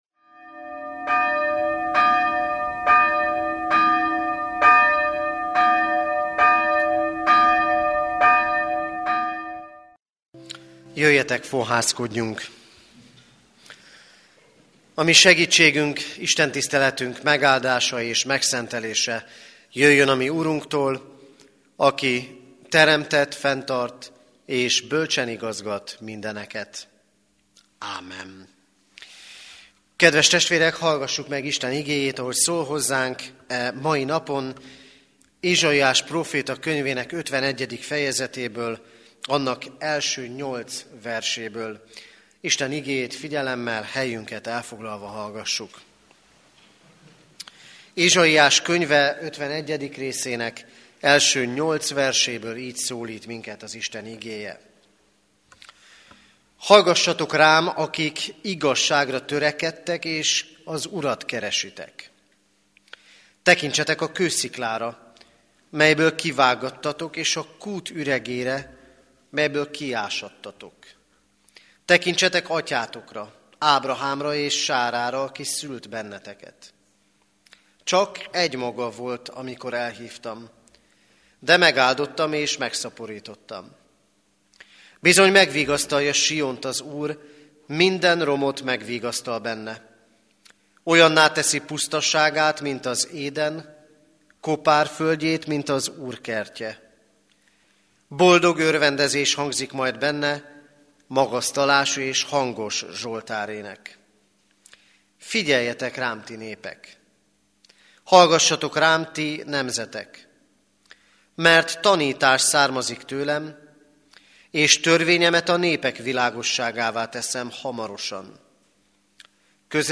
Igehirdetések Aki örök (Katonatelep)